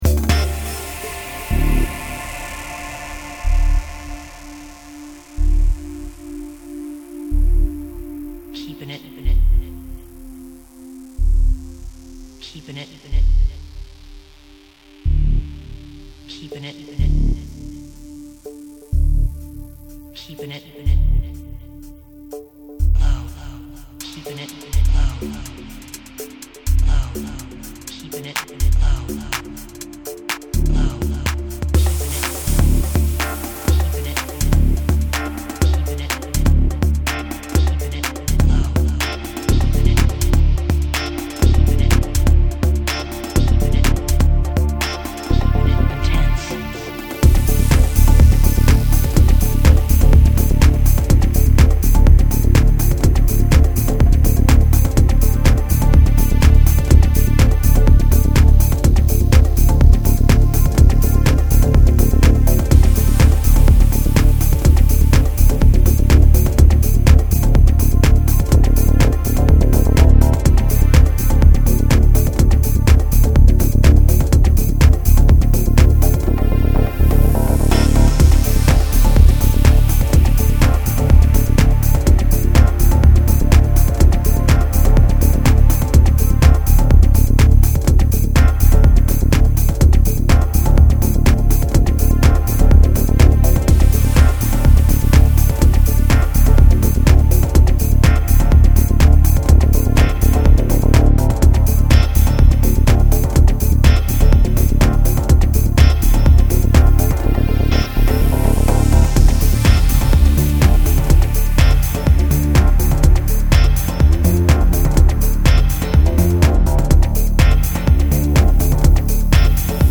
Deep techno, ambient/dub
Keepin' It Low(deep tech-house) or